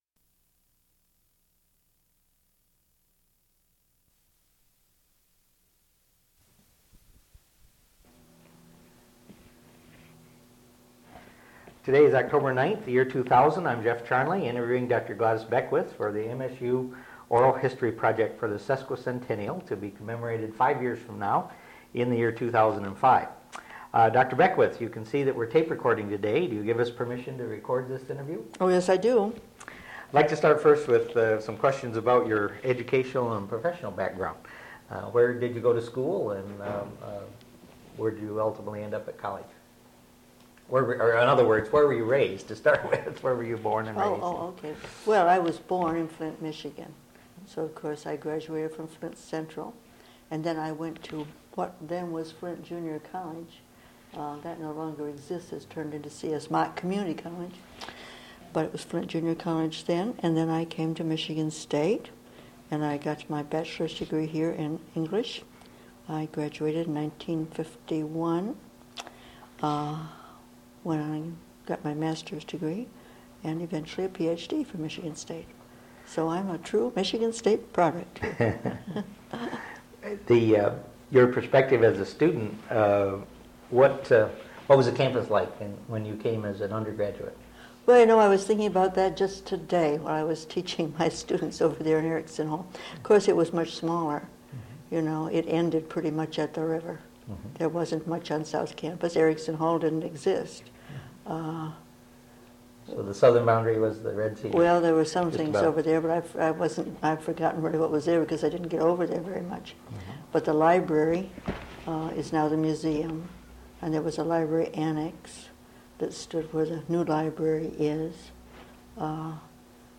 Original Format: Audiocassettes
Sesquicentennial Oral History Project